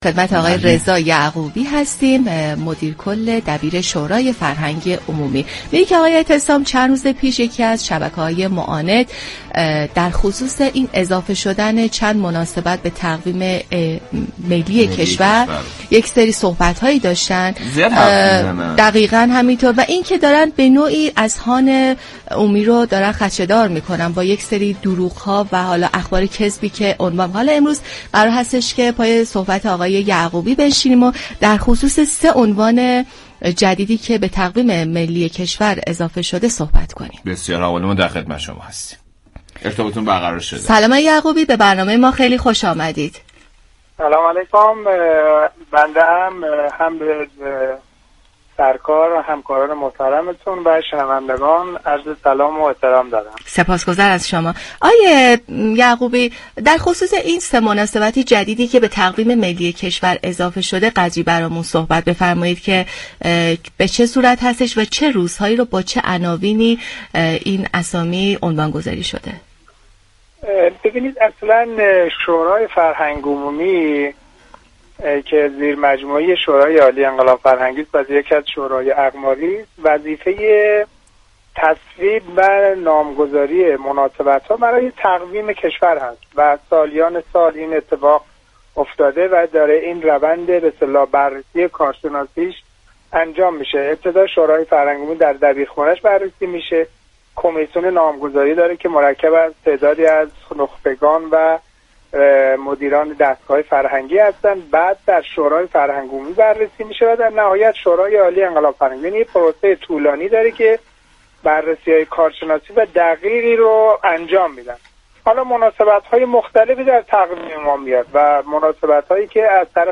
به گزارش پایگاه اطلاع رسانی رادیو تهران، رضا یعقوبی مدیر كل دبیرخانه شورای فرهنگ عمومی كشور در گفت و گو با «سعادت آباد» اظهار داشت: شورای فرهنگ عمومی زیرمجموعه‌ی شورای عالی انقلاب فرهنگی است و وظیفه تصویب و نامگذاری مناسبت‌های تقویم كشور را بر عهده دارد.